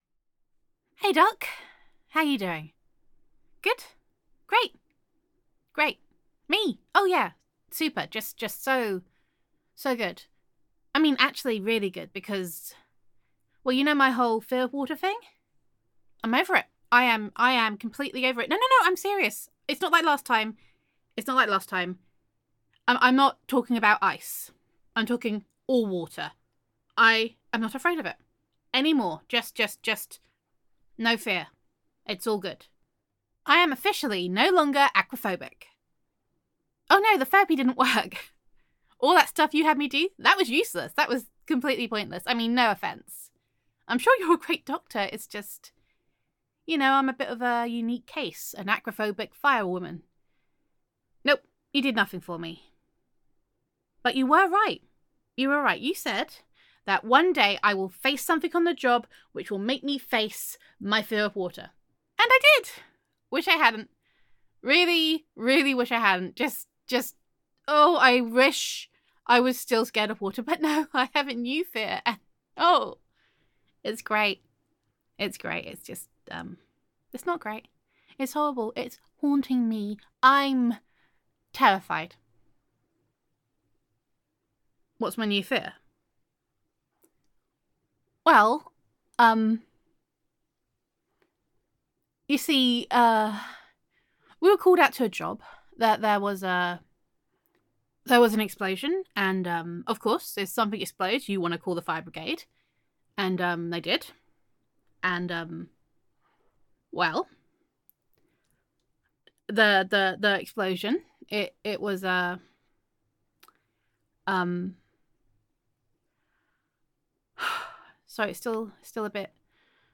[F4A] My New Problem [Aquaphobia][Fire Woman Honey][Therapist Listener][Suddenly Cured][Gender Neutral][You Were Right, but Not the Way You Think]